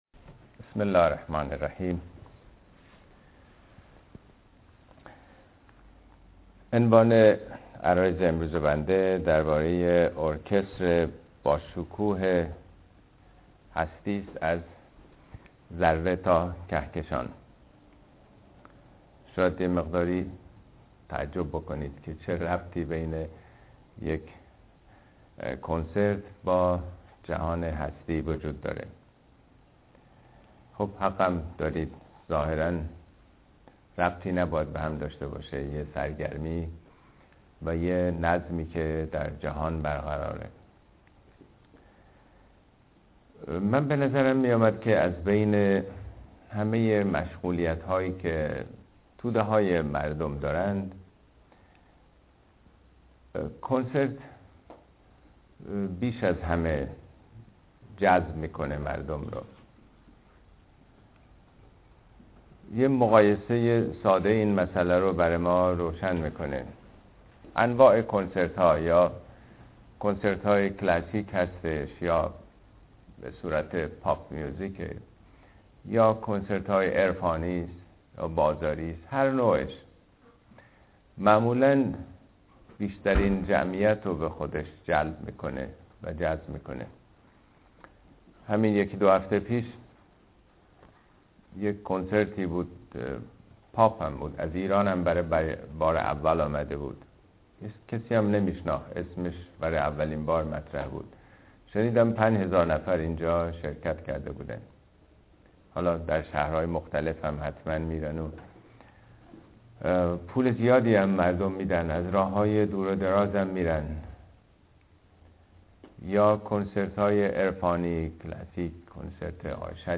خدا در طبيعت كنسرت باشكوه هستى در ۲ بخش (از ذره تا كهكشان) توصيه ميشود براىاستماع سخنرانى از گزينه STREAM استفاده كنيد.